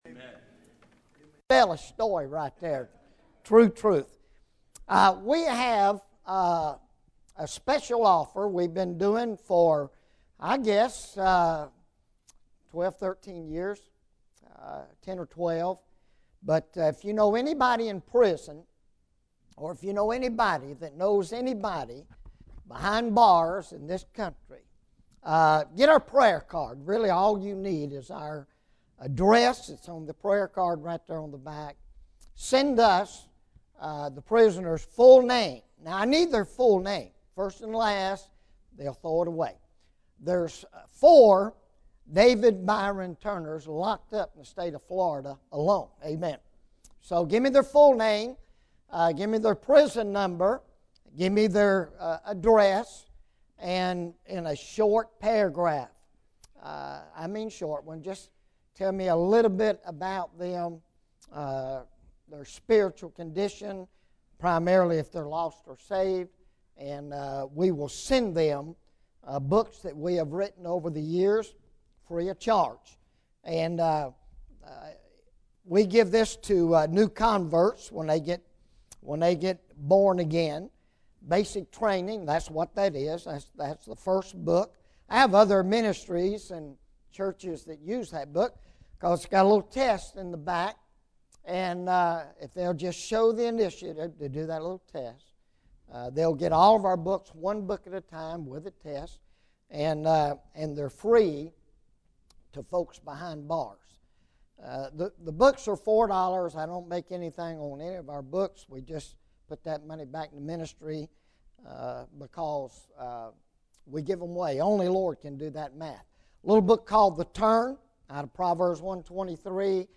Bible Text: II Timothy 4 | Preacher